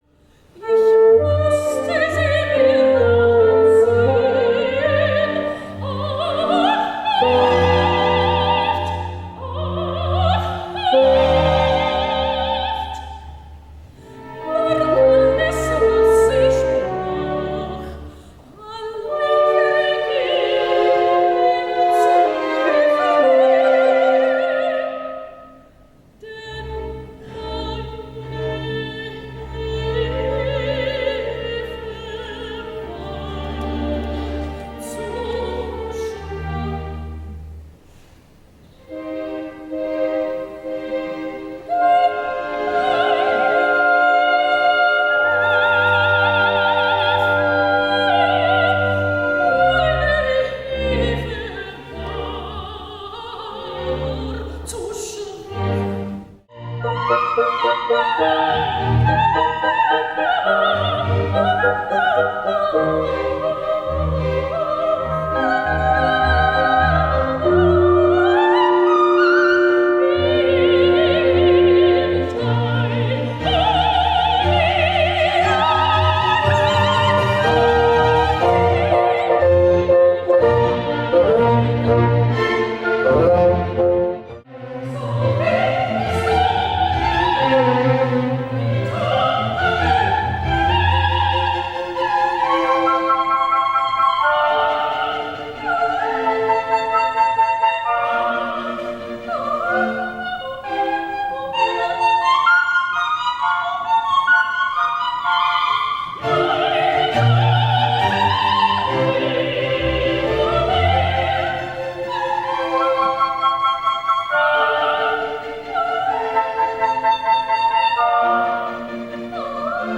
Koloratursopran